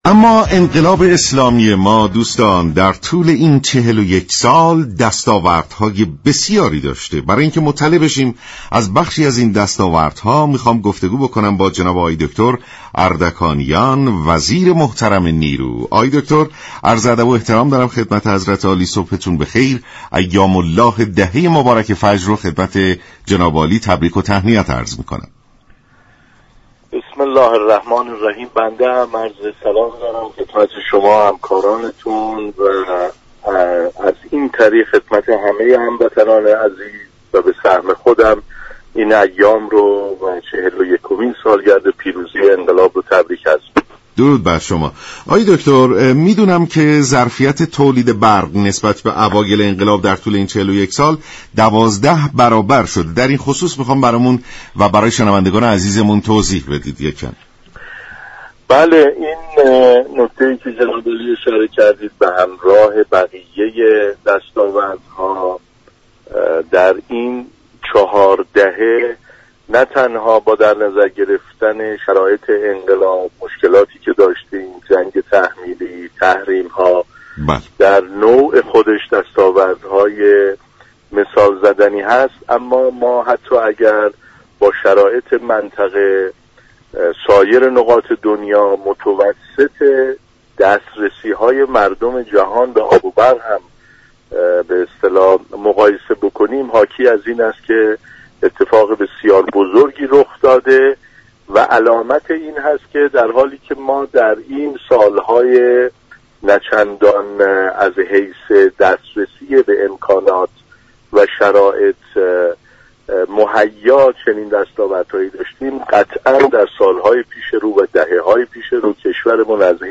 «رضا اردكانیان» وزیر نیرو در گفت و گو با رادیو ایران گفت: در ابتدای پیروزی انقلاب اسلامی حدود 4 هزار روستا به برق دسترسی داشته اند این در حالی است كه در چهار دهه اخیر، 53 هزار روستا به این میزان افزوده شده است.